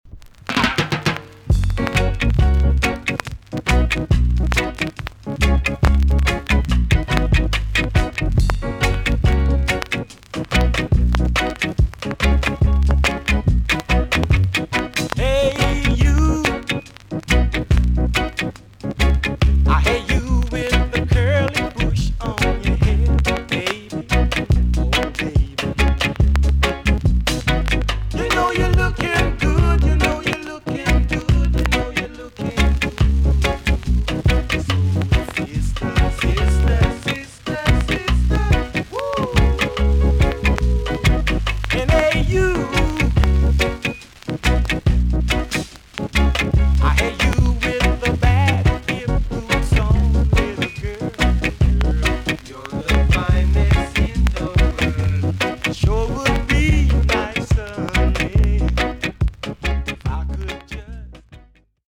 TOP >REGGAE & ROOTS
VG+ 軽いチリノイズが入ります。